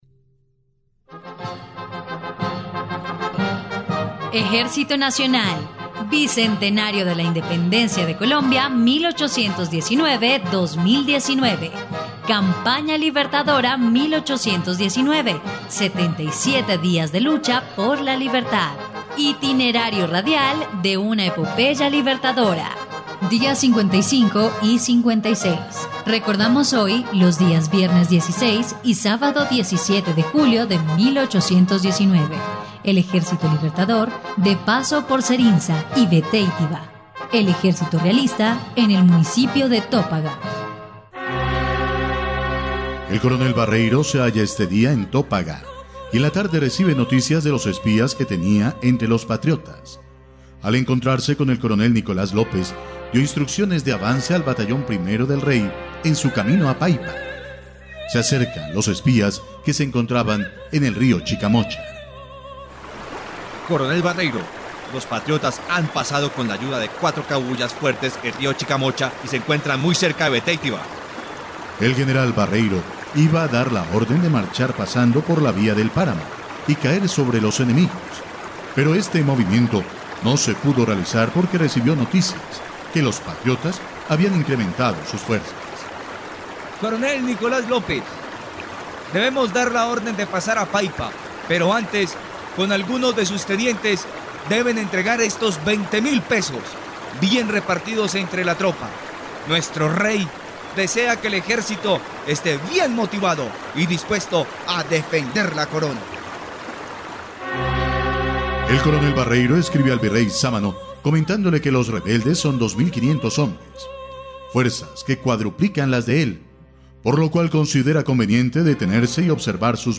dia_55_y_56_radionovela_campana_libertadora_0.mp3